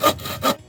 crafting_complete.ogg